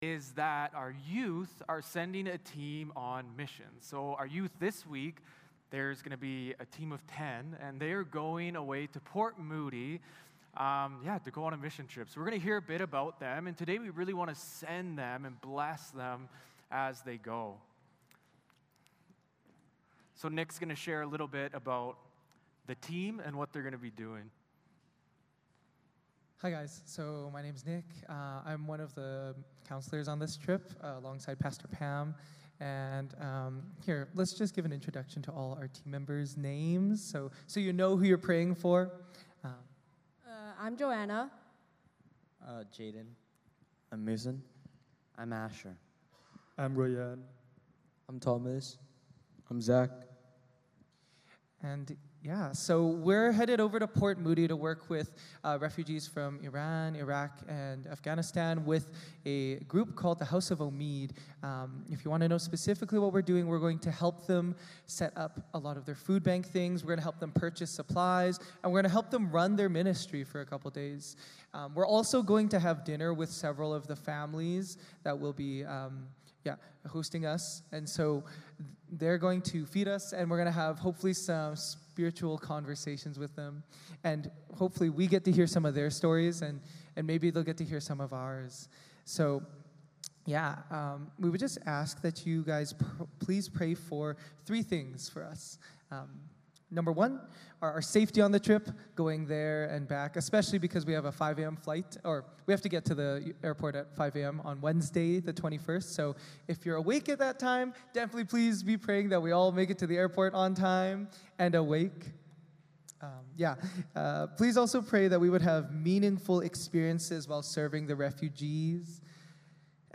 2-5 Service Type: Sunday Morning Service Passage